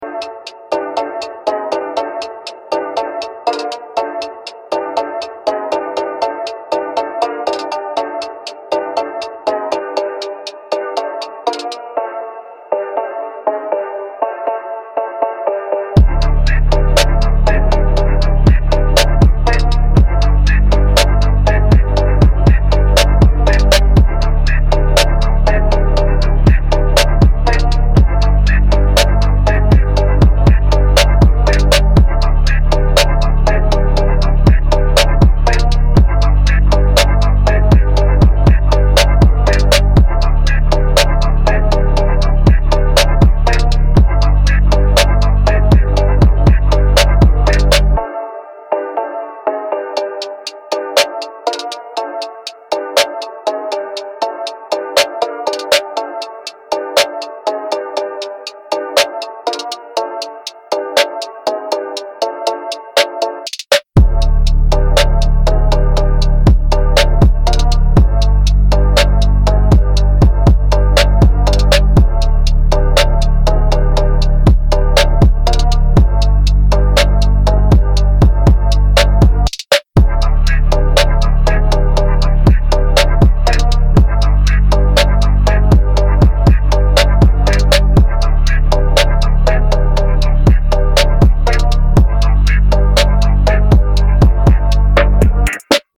Genres:Music-Instrumental